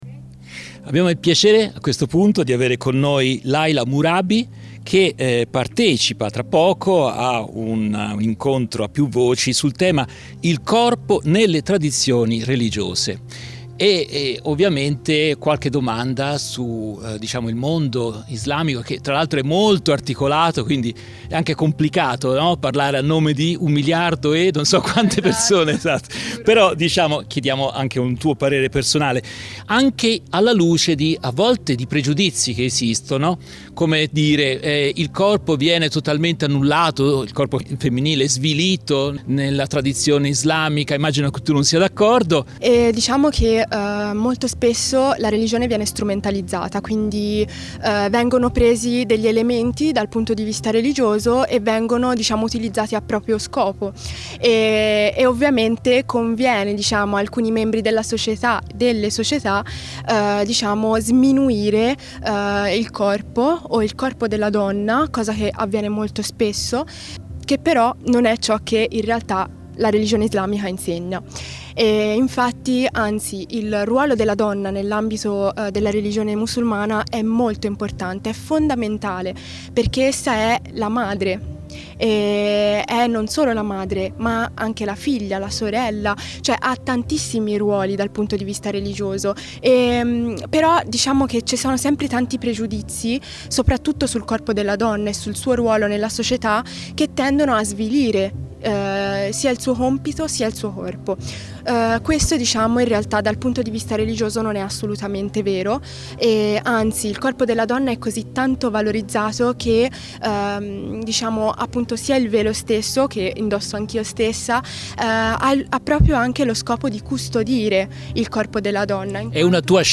Voci dal Villa Aurora Meeting 2023